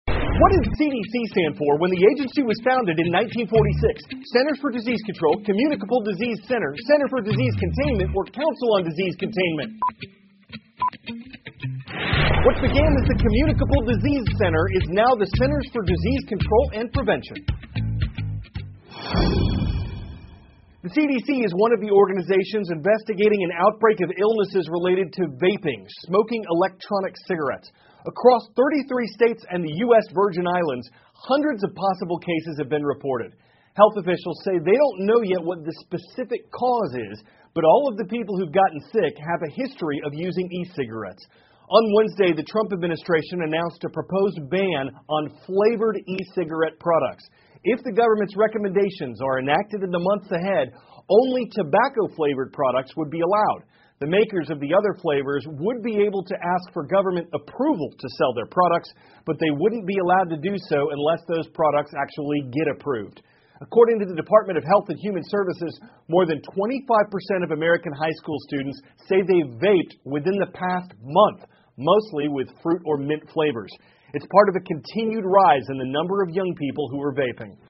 美国有线新闻 CNN 特朗普政府计划禁止销售调味电子烟 听力文件下载—在线英语听力室